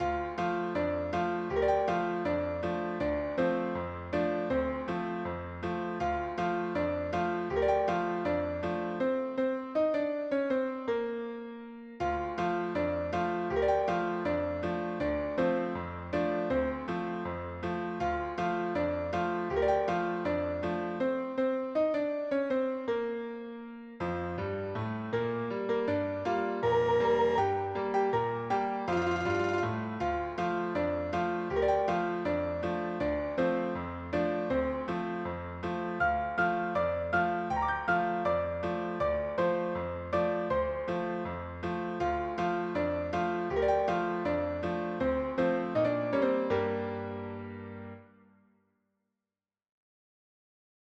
Cheerful
Ragtime cover
Piano only